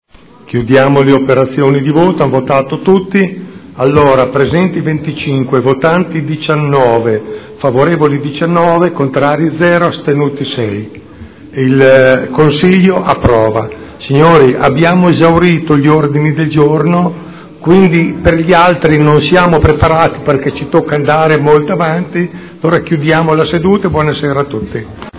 Presidente — Sito Audio Consiglio Comunale
Seduta del 15/10/2012